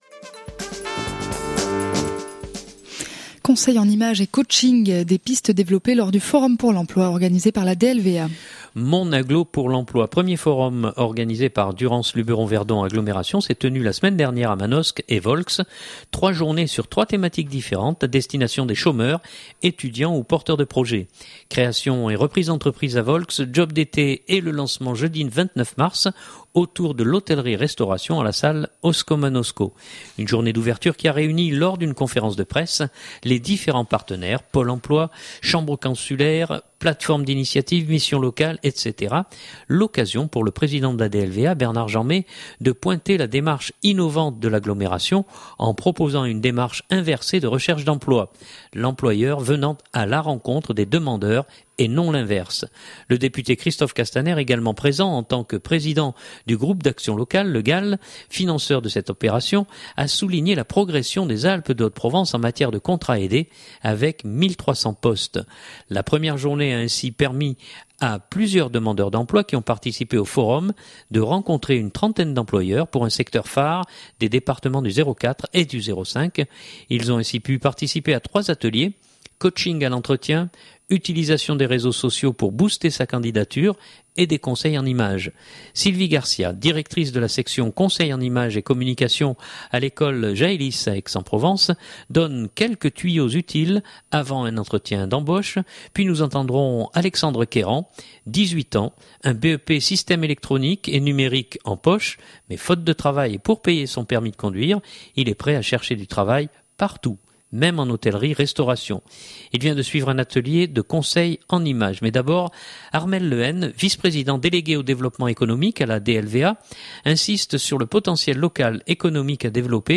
Mais d’abord Armel le Hen, vice-président délégué au développement économique à la DLVA insiste sur le potentiel local économique à développer et sur la création et la reprise d’entreprises qui fonctionne bien dans les Alpes du Sud.